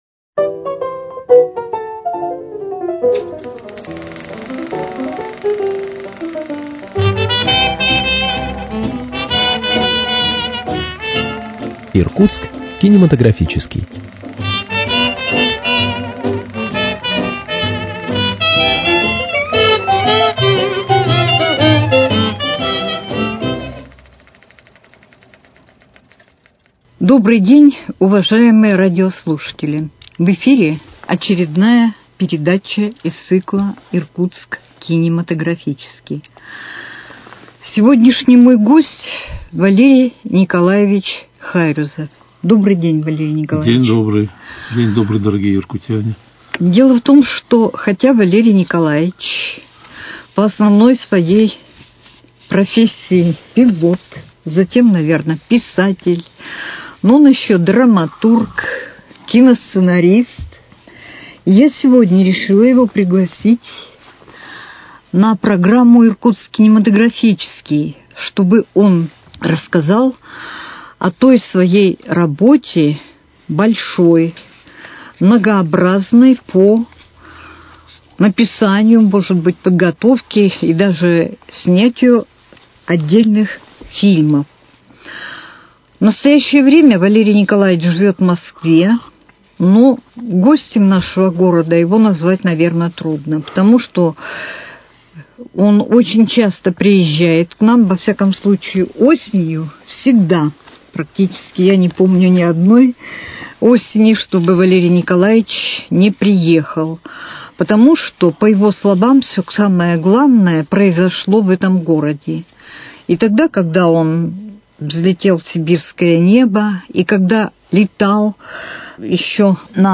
Иркутск кинематографический: Беседа с писателем Валерием Хайрюзовым